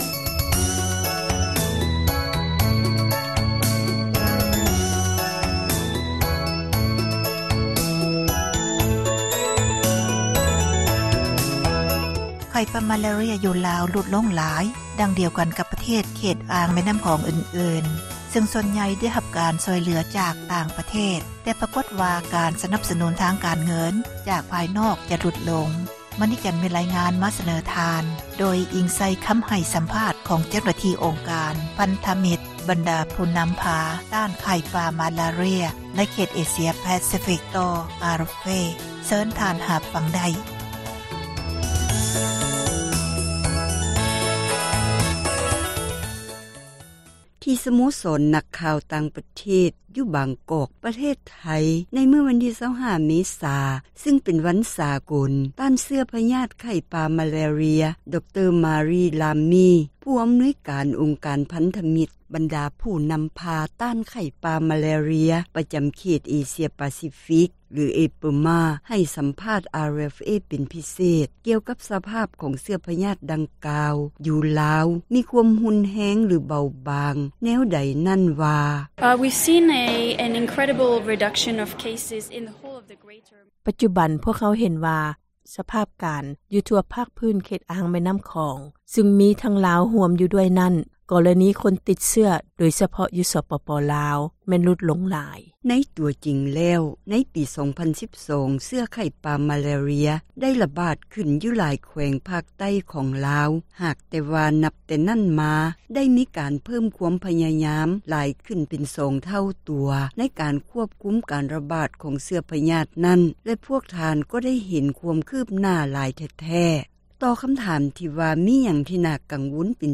ທີ່ສະໂມສອນນັກຂ່າວ ຕ່າງປະເທດ ຢູ່ບາງກອກ ປະເທດໄທ
ໃຫ້ສັມພາດ RFA ເປັນພິເສດ